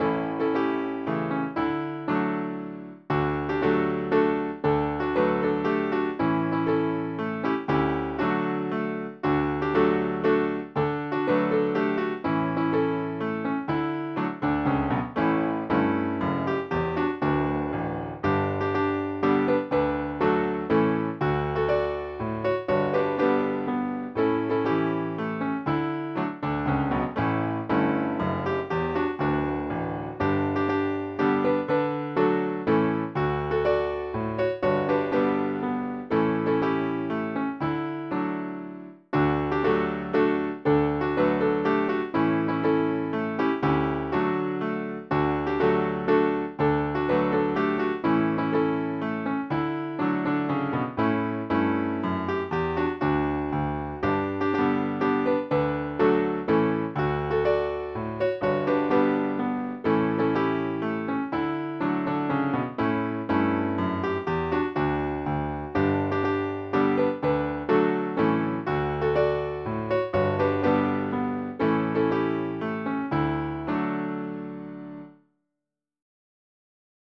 Mazurek Dąbrowskiego (podkład)